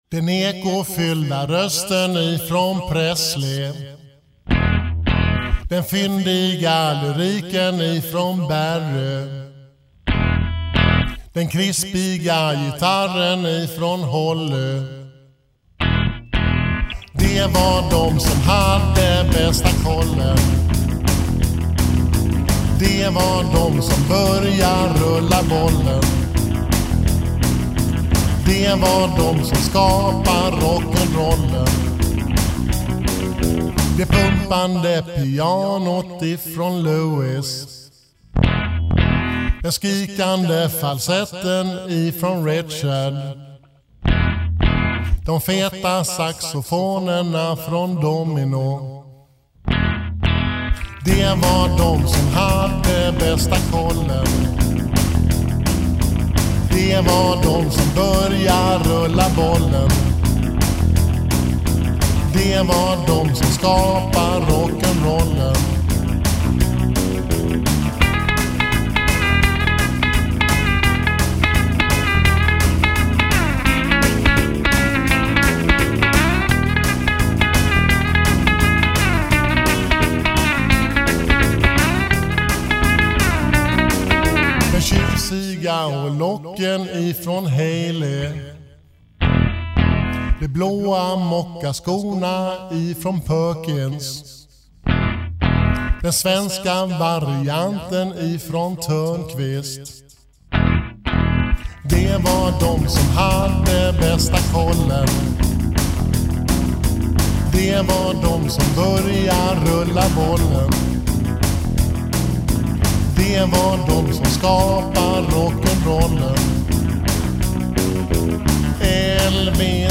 Lyrik: En rocklåt.